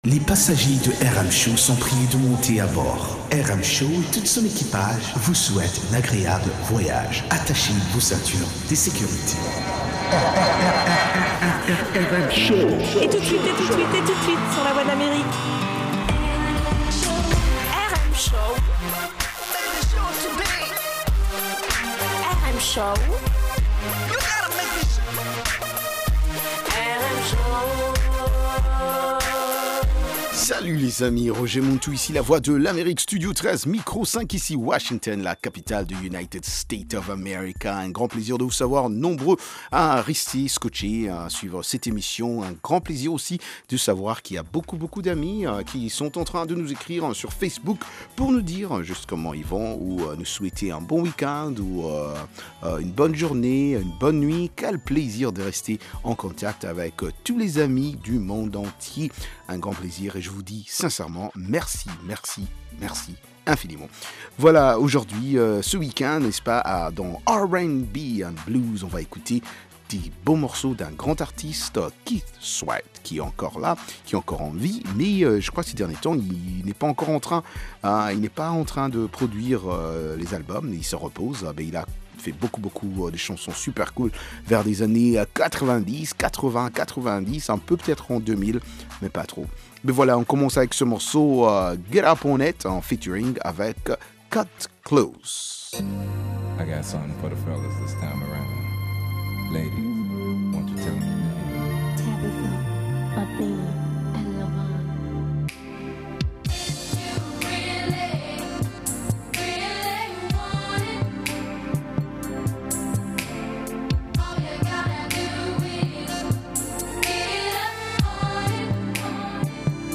R&B et Rock